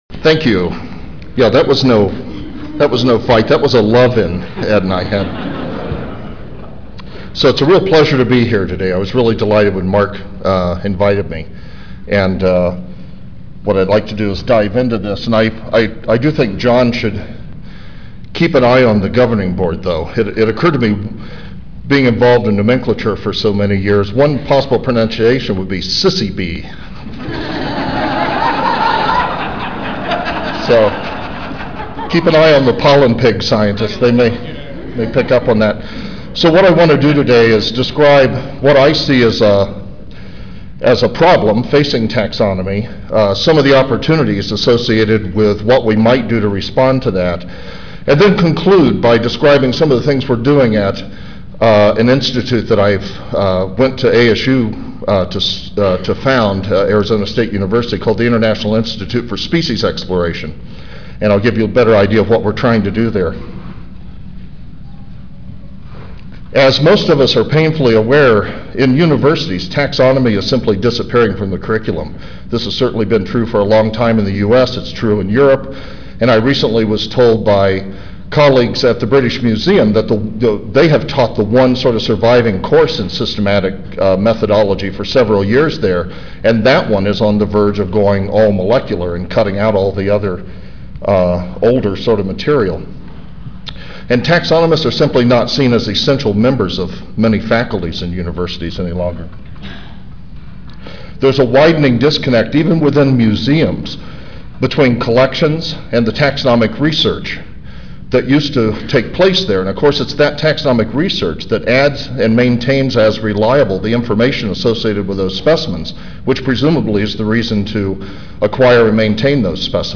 4:05 PM 0617 The Systematics, Evolution and Biodiversity Invited Lecture: Unrepentant Taxonomy for the 21st Century